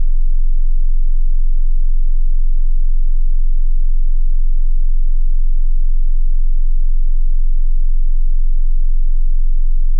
Это симуляция несущей конструкции, в которой «зазорам» (колебаниям) позволили распространяться под нагрузкой. Это основной гул частотой 22 Гц — звук системы, удерживающей единство, — на который накладывается треск микротрещин.
Это звук разрушения базового каркаса из-за того, что поверхностный слой отказался выполнять свою работу.